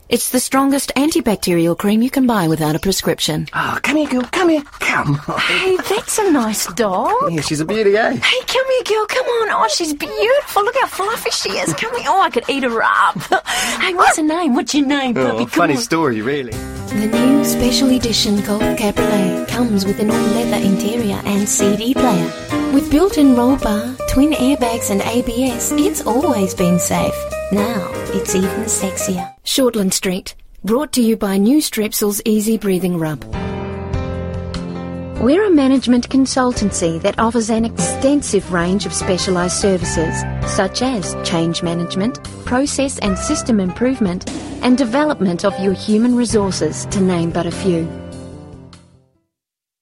Demo
Adult
new zealand | natural
VOICEOVER GENRE
COMMERCIAL 💸